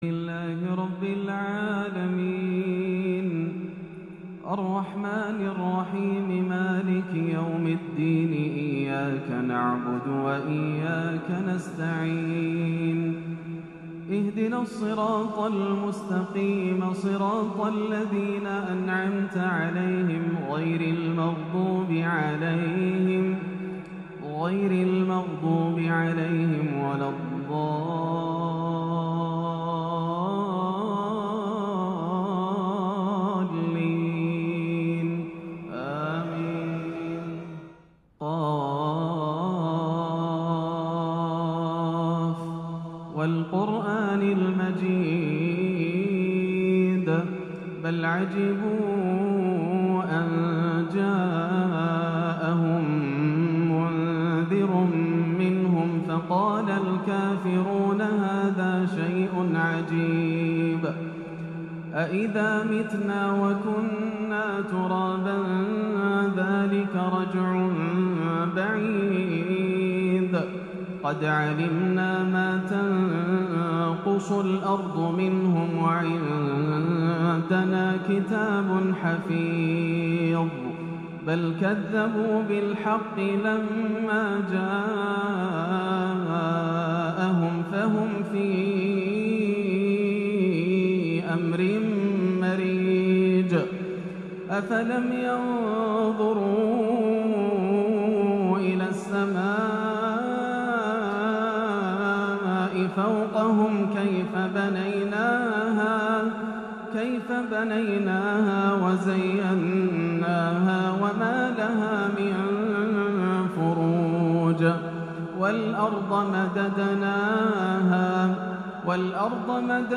سورة ق - تلاوة رائعة بالصبا والعراقي - الثلاثاء 8-2-1438 > عام 1438 > الفروض - تلاوات ياسر الدوسري